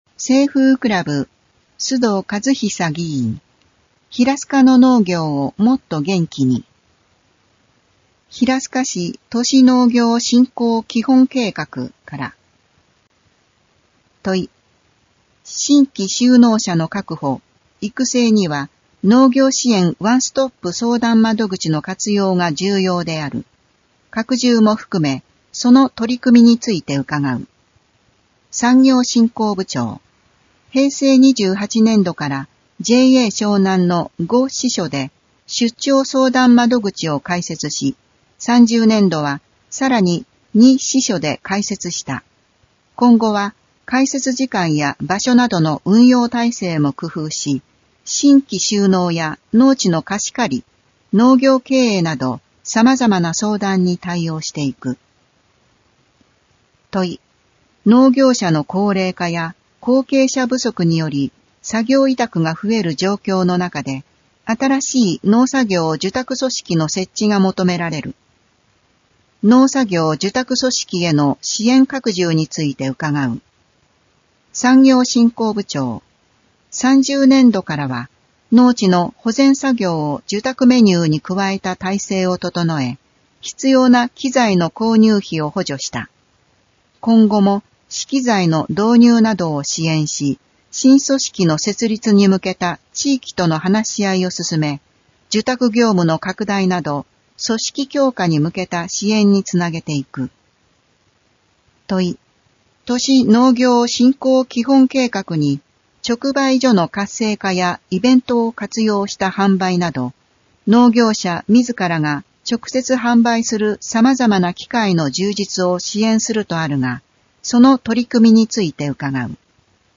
声の議会だより　平成31年3月定例会号